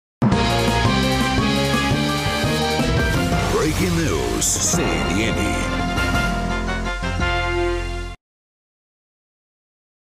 Download Fresh news sound effect for free.